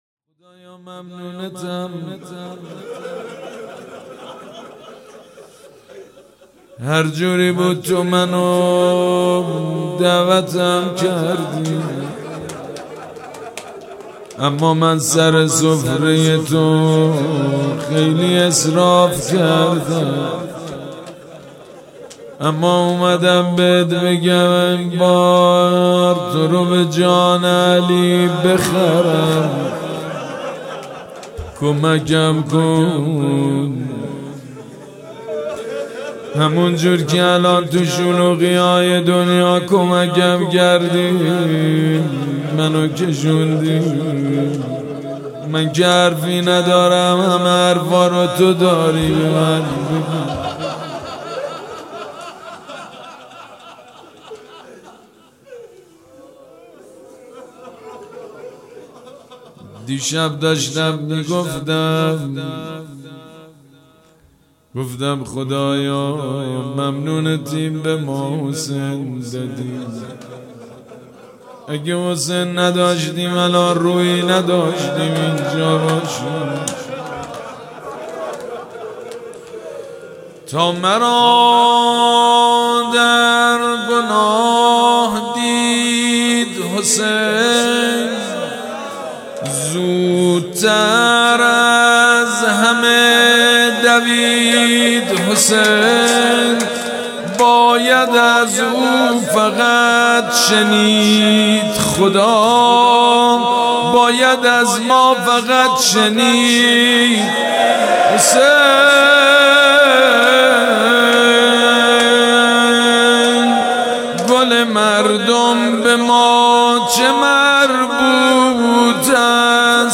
مراسم مناجات شب بیستم ماه رمضان
حسینیه ریحانة‌الحسین(س)
روضه